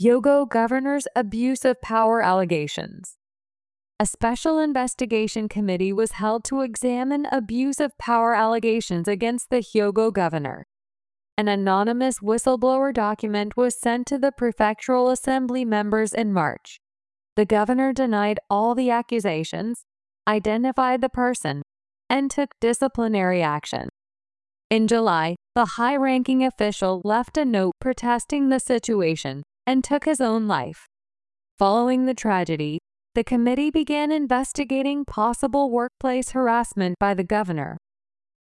【スロースピード】
上記の英文を表示しながらスロースピードの音声を通しで再生し、自分の声をかぶせてオーバーラッピングします。